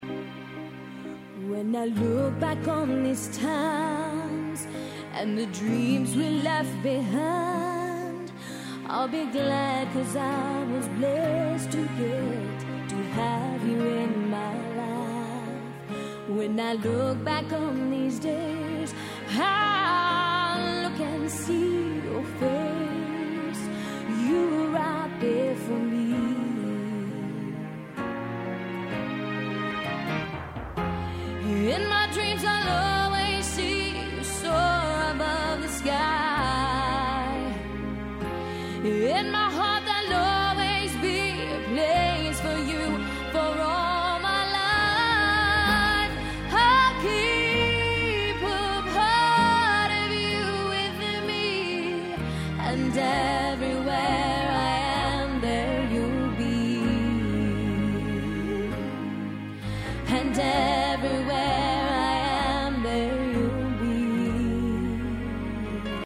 C  O  U  N  T  R  Y     C  O  V  E  R  S